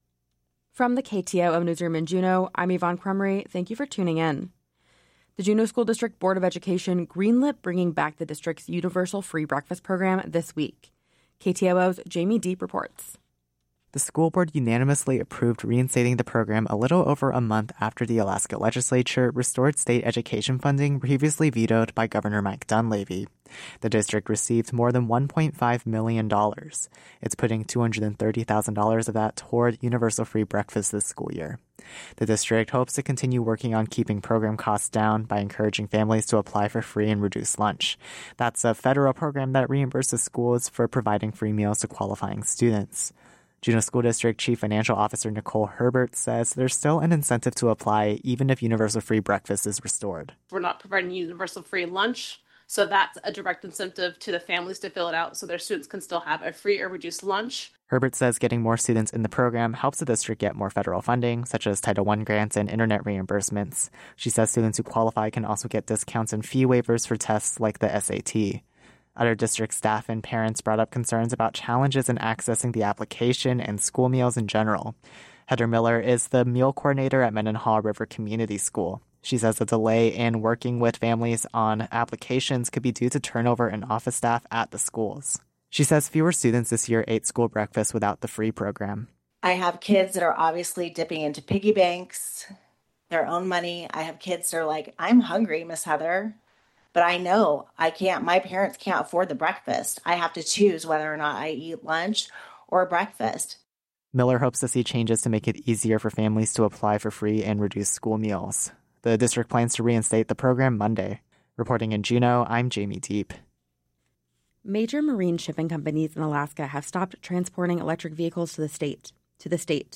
Newscast – Thursday, Sept. 11, 2025 - Areyoupop